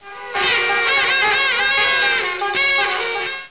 00692_Sound_carnival.mp3